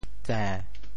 寨 部首拼音 部首 宀 总笔划 14 部外笔划 11 普通话 zhài 潮州发音 潮州 zê7 文 中文解释 寨 <名> (形声。